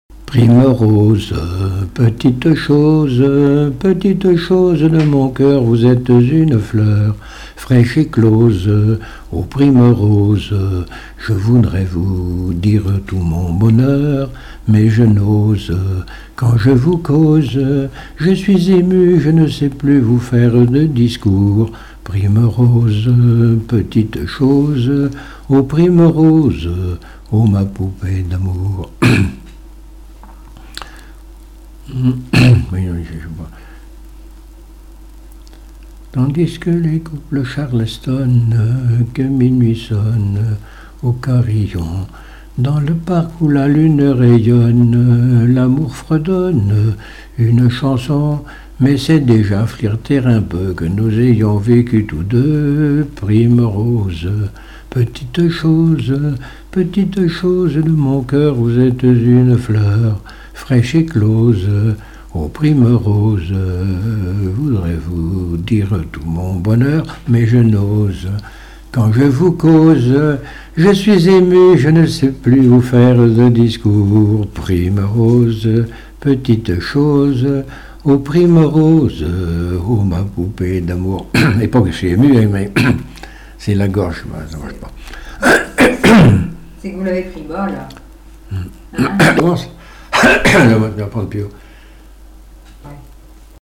Mémoires et Patrimoines vivants - RaddO est une base de données d'archives iconographiques et sonores.
Genre strophique
Chansons et témoignages
Pièce musicale inédite